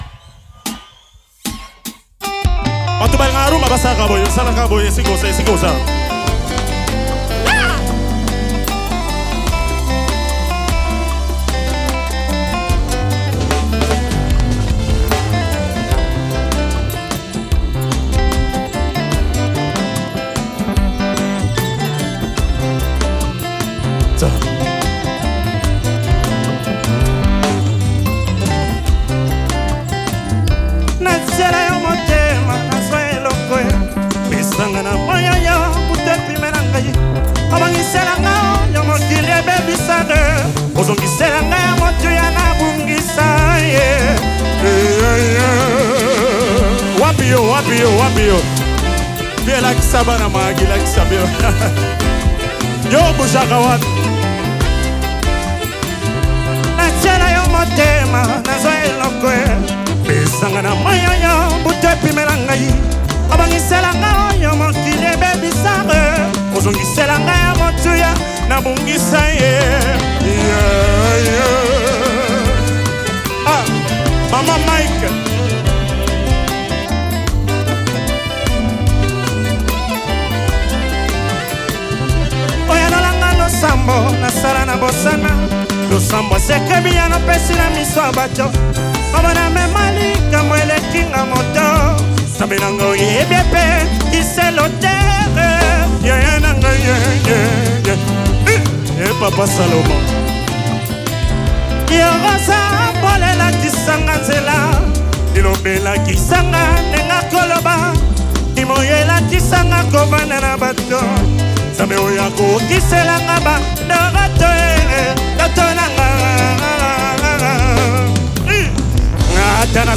Dix candidats qui ont franchi l’étape des quarts de finale du concours le 27 Juillet passé ont livré des prestations émouvantes, axées sur le thème « Chantons Ituri », en interprétant des titres phares du répertoire gospel de la province.